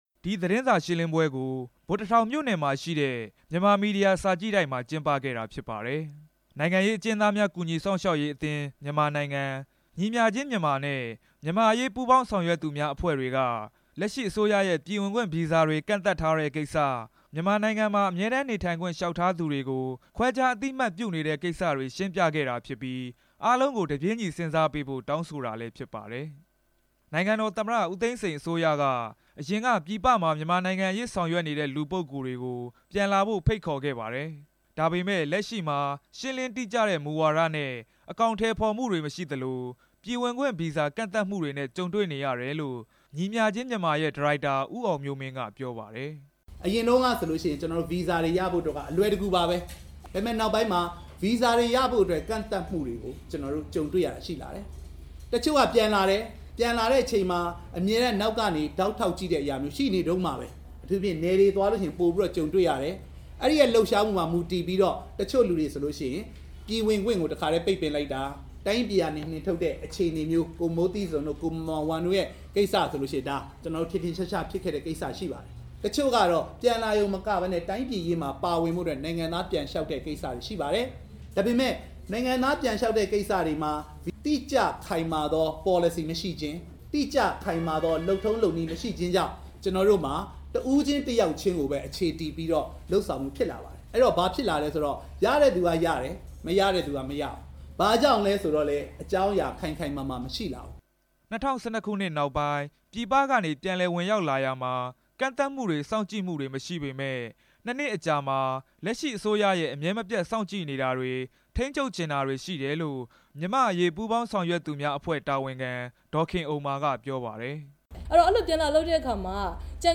ရန်ကုန်မြို့ ဗိုလ်တစ်ထောင်မြို့နယ်က မြန်မာမီဒီယာ စာကြည့်တိုက်မှာ အဖွဲ့အစည်း ၃ ခုပူးတွဲပြီး သတင်းစာရှင်းလင်းပွဲကျင်းပစဉ် ပြောခဲ့တာပါ။